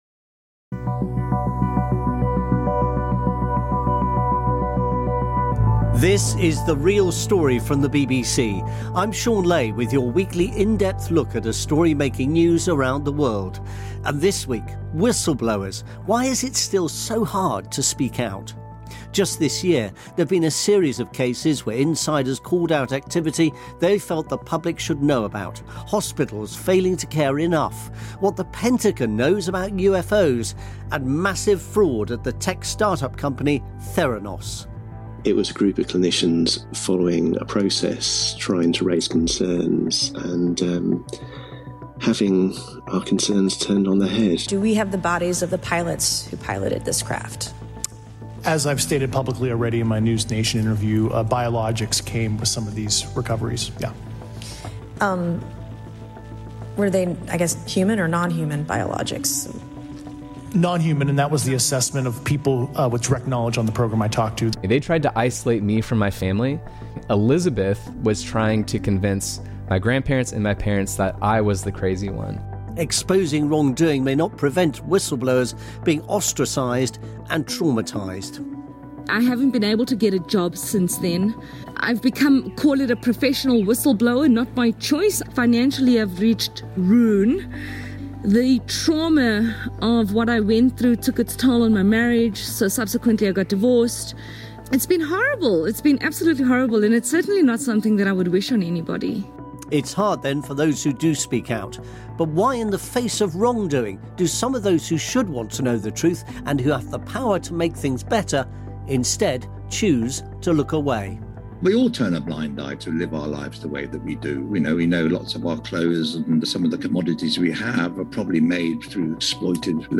—> NPR Marketplace Morning Report Interview.